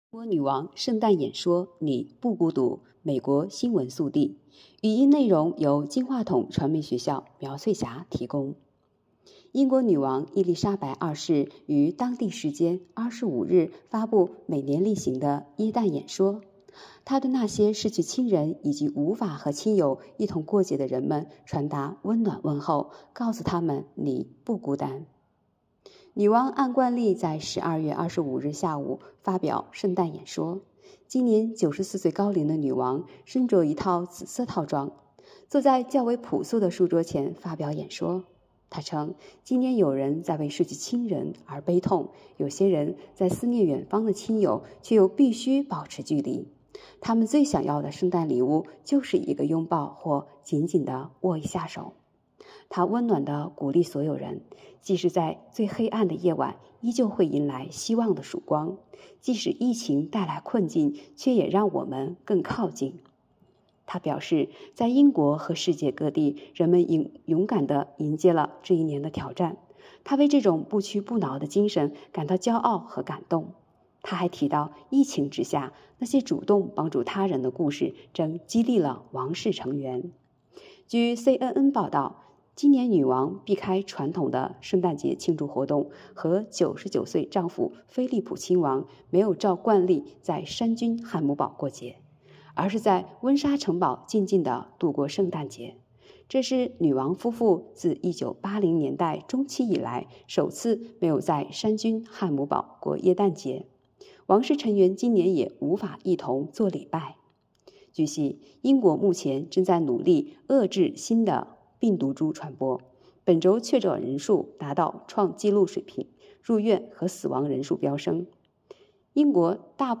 【听新闻学播音】英国女王圣诞演说：你不孤独！